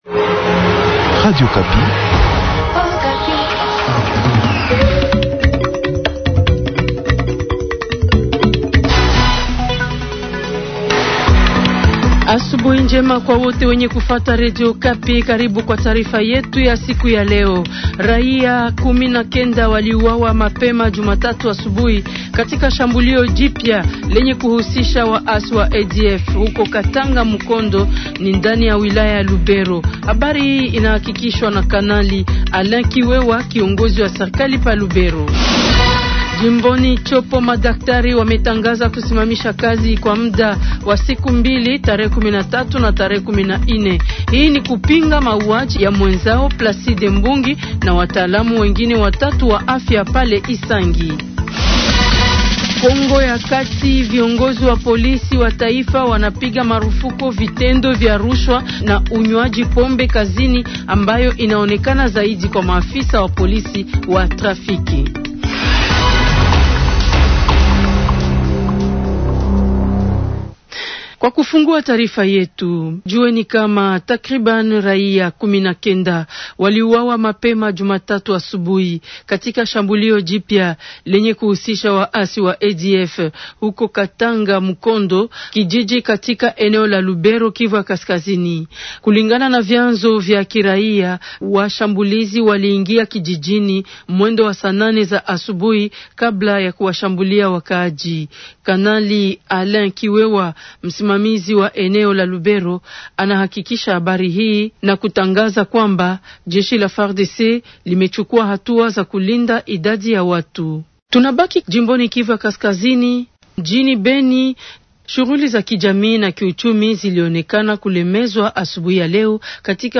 Journal swahili du mardi Matin 14 octobre 2025.